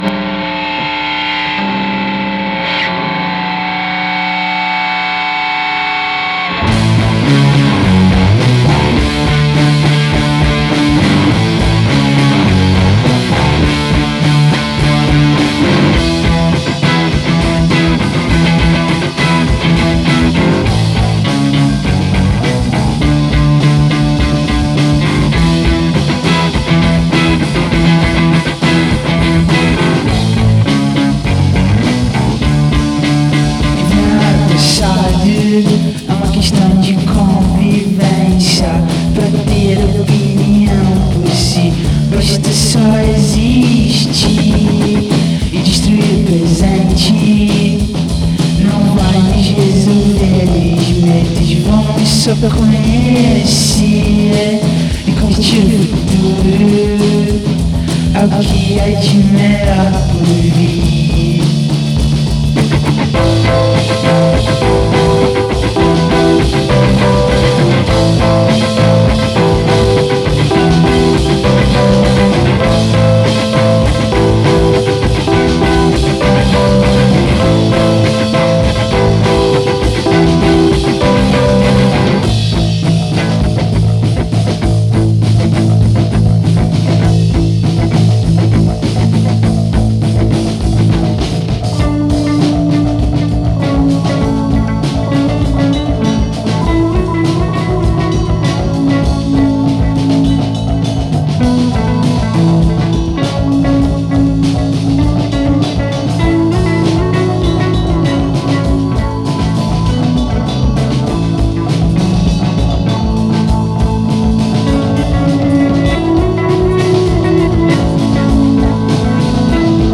un garage hautement psychédélique